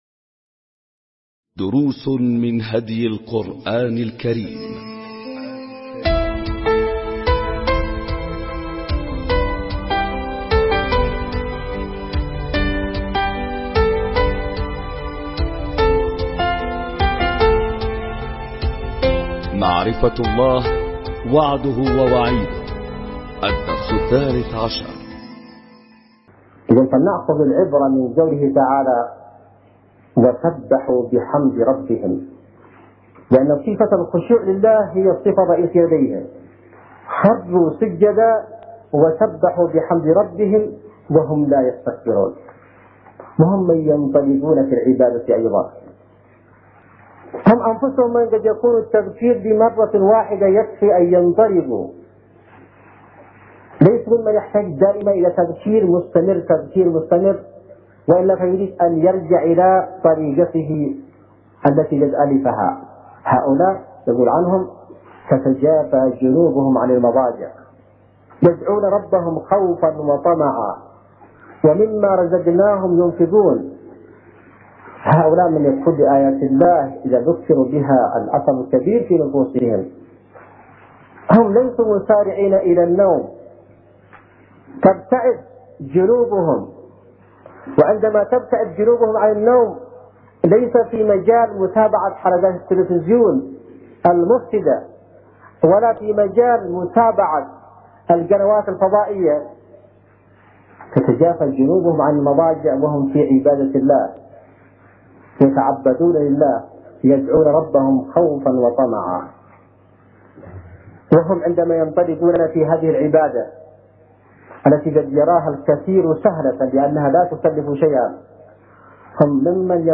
دروس من هدي القرآن الكريم معرفة الله – وعده ووعيده – الدرس الثالث عشر ملزمة الأسبوع | اليوم الخامس ألقاها السيد / حسين بدرالدين الحوثي